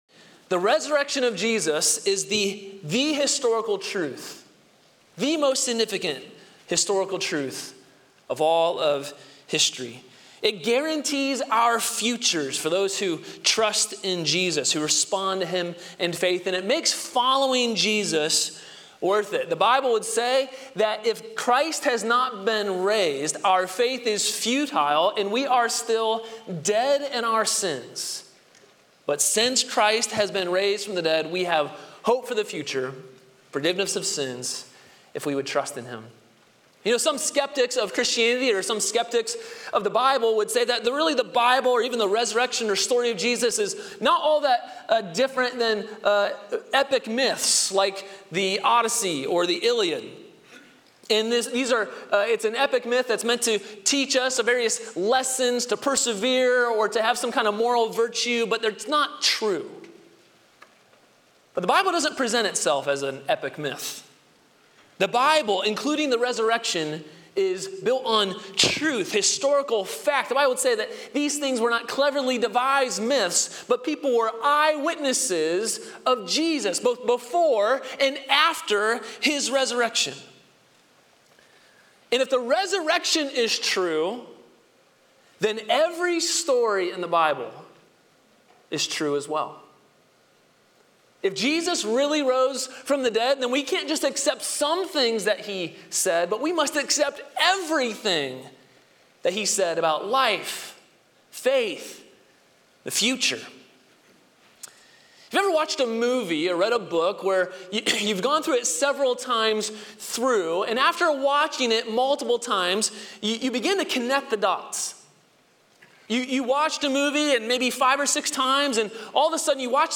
Sermons • Grace Polaris Church